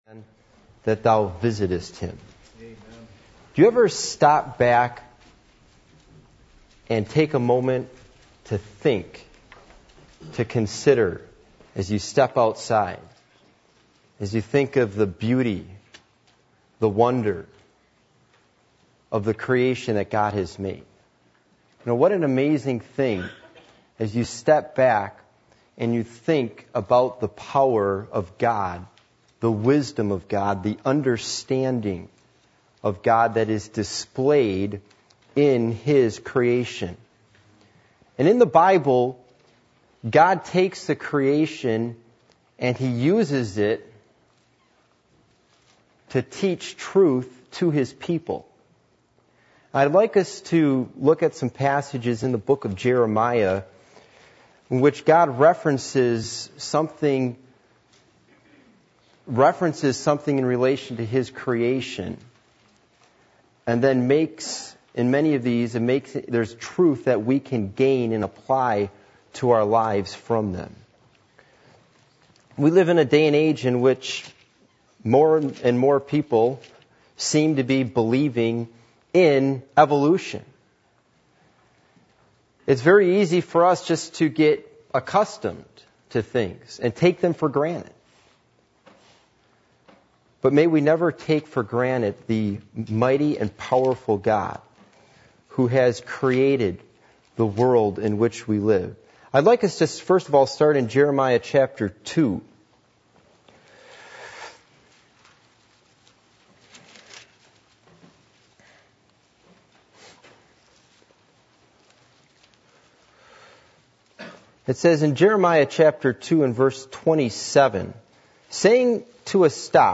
Jeremiah 32:17 Service Type: Midweek Meeting %todo_render% « What Does God Ask From Us When We Come To Church?